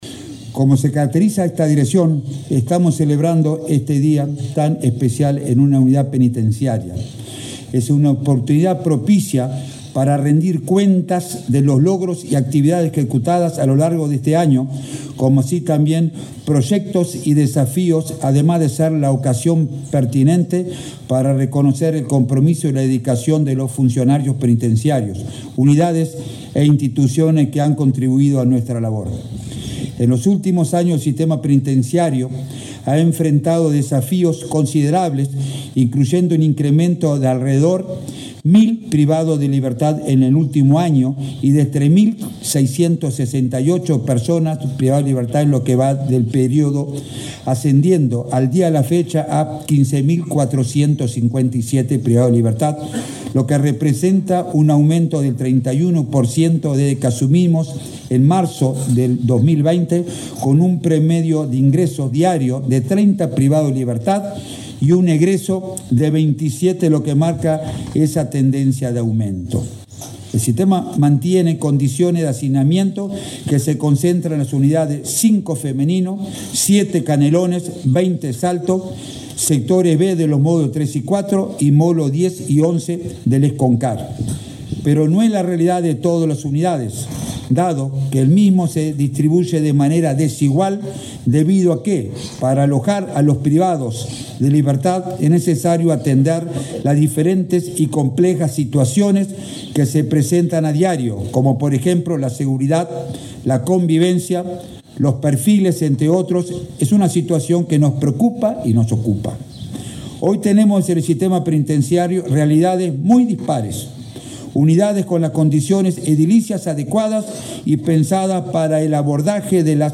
Palabras del director del INR, Luis Mendoza
El director del Instituto Nacional de Rehabilitación, Luis Mendoza, fue el orador central del acto por el 13.° aniversario de ese organismo, realizado